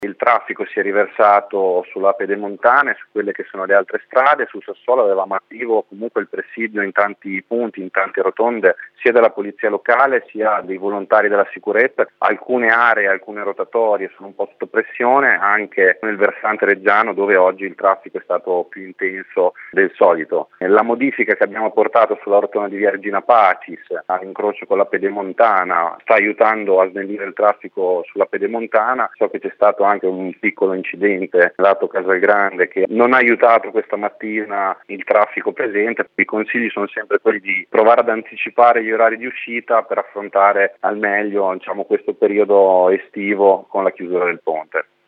A fare il punto su come sono andate queste prime ore è il sindaco di Sassuolo Matteo Mesini